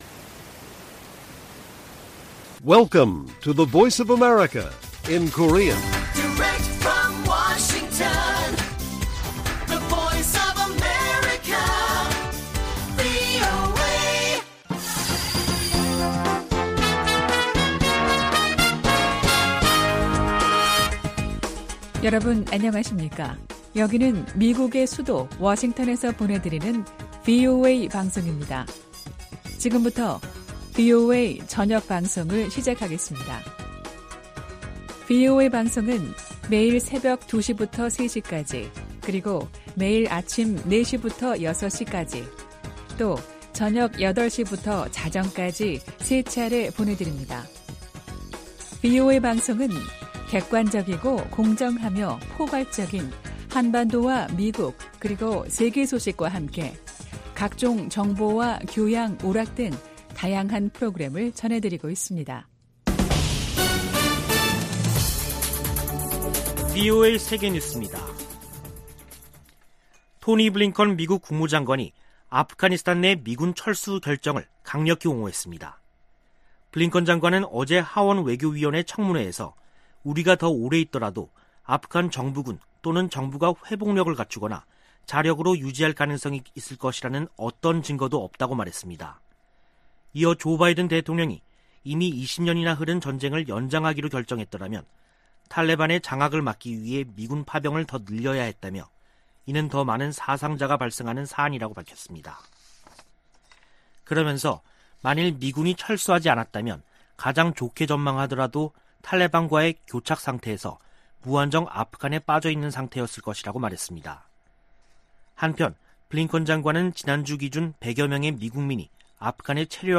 VOA 한국어 간판 뉴스 프로그램 '뉴스 투데이', 2021년 9월 14일 1부 방송입니다. 성 김 미국 대북특별대표는 미국은 북한에 적대적 의도가 없다며 북한이 대화 제의에 호응할 것을 거듭 촉구했습니다. 북한의 신형 장거리 순항미사일 시험발사는 도발이라기 보다는 무기체계 강화의 일환이라고 미국의 전문가들이 평가했습니다. 북한의 미사일 발사는 주민들의 식량을 뺏는 행위라고 국제인권단체가 비판했습니다.